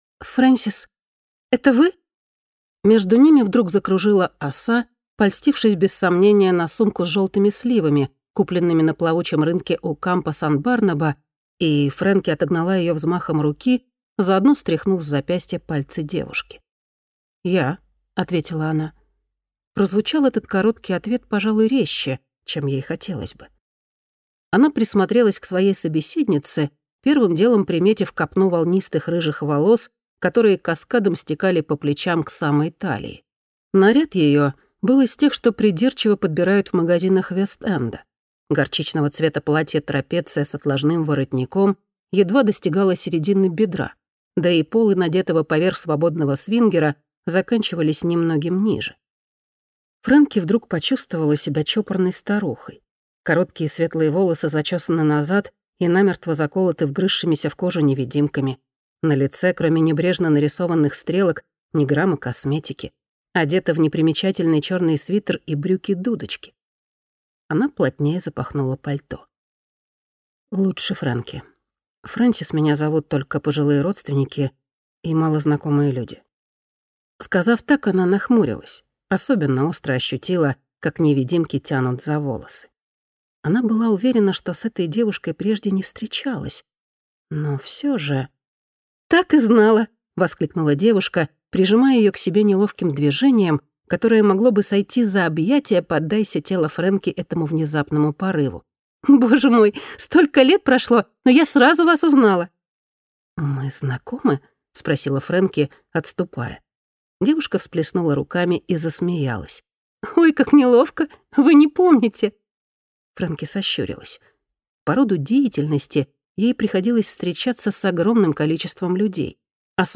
Аудиокнига Дворец утопленницы | Библиотека аудиокниг